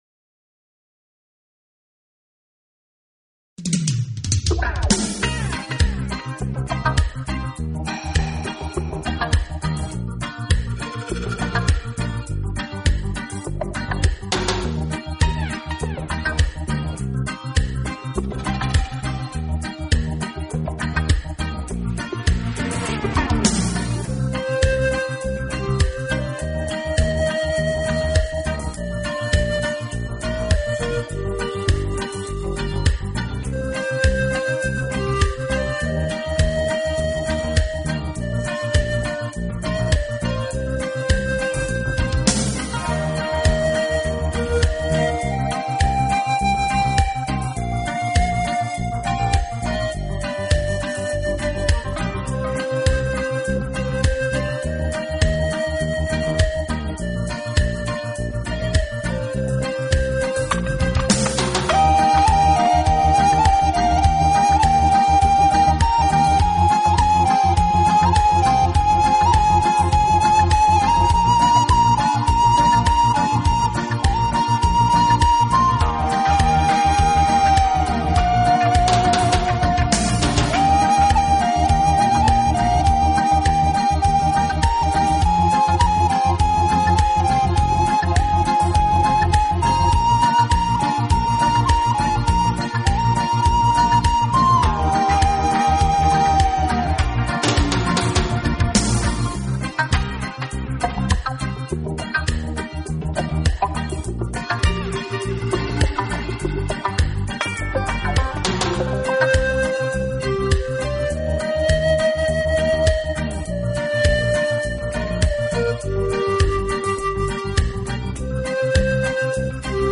同时其录音又极其细致、干净、层次分明，配器简洁明了，是